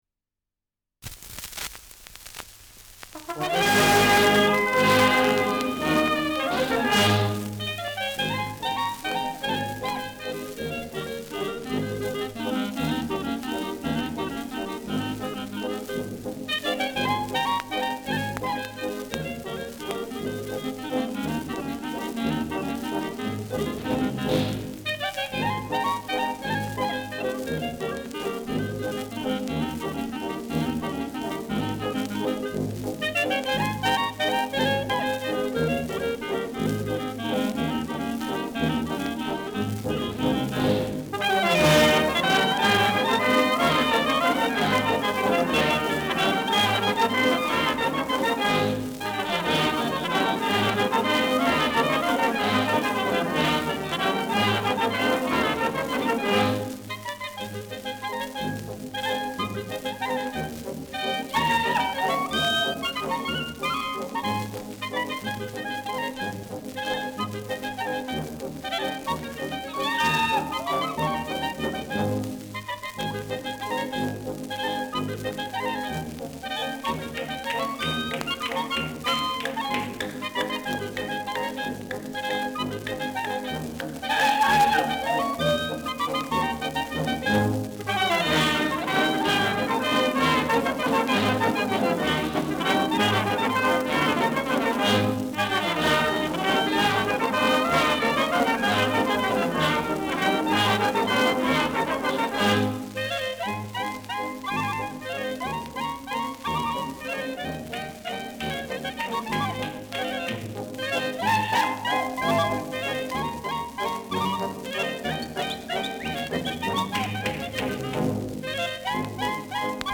Schellackplatte
Leiert stärker : Gelegentliches Knacken
[Berlin] (Aufnahmeort)